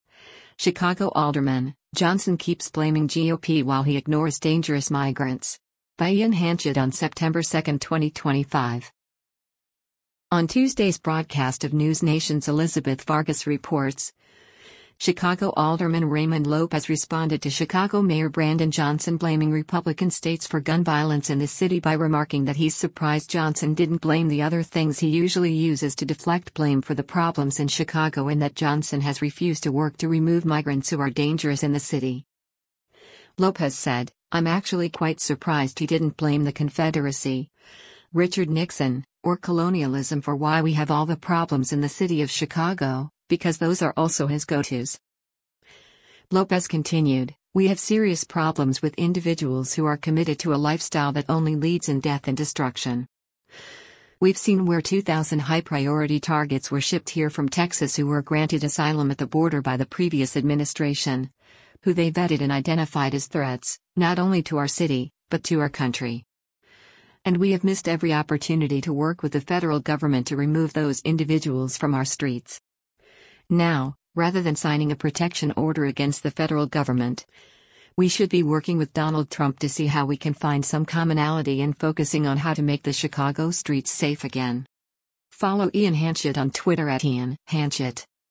On Tuesday’s broadcast of NewsNation’s “Elizabeth Vargas Reports,” Chicago Alderman Raymond Lopez responded to Chicago Mayor Brandon Johnson blaming Republican states for gun violence in the city by remarking that he’s surprised Johnson didn’t blame the other things he usually uses to deflect blame for the problems in Chicago and that Johnson has refused to work to remove migrants who are dangerous in the city.